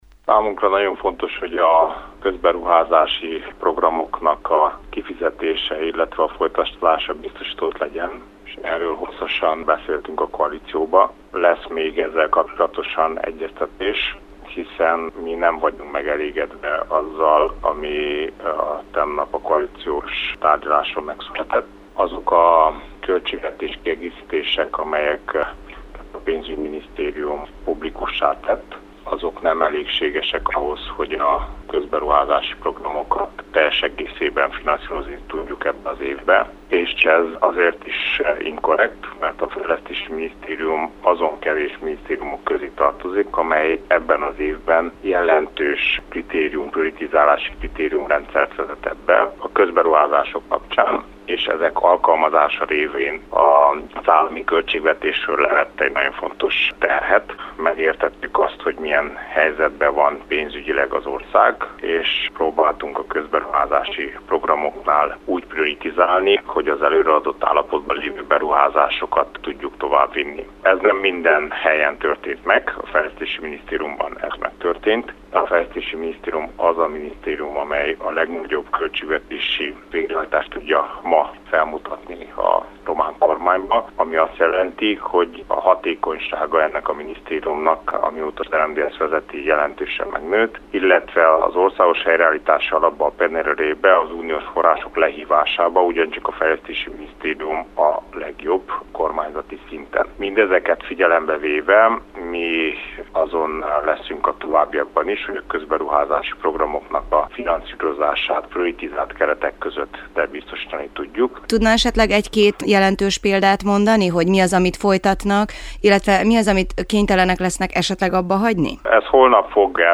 Nem elég arra, hogy a közberuházási programokat teljes egészében finanszírozzák - mondta rádiónknak a fejlesztési miniszter.
interjúja